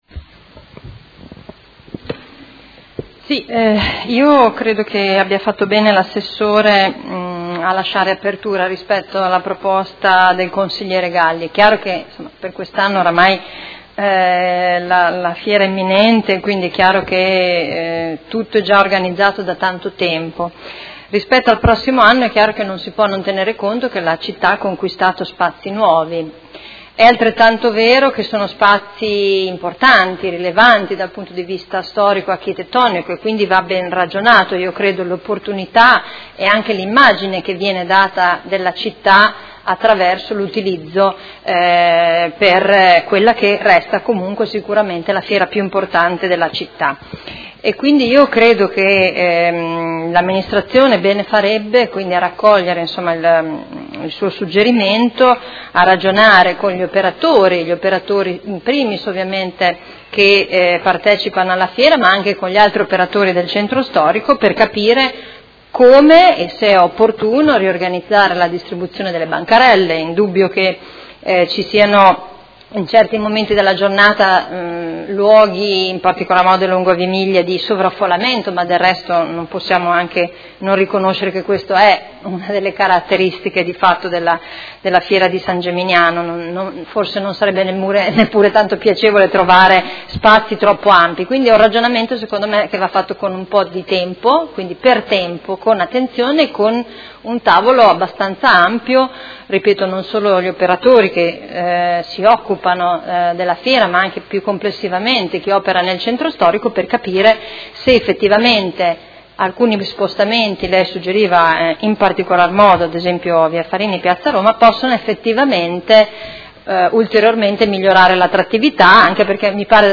Seduta del 28 gennaio. Interrogazione del Consigliere Galli (F.I.) avente per oggetto: Perché non allargare a Via Farini/Piazza Roma le bancherelle per S. Geminiano?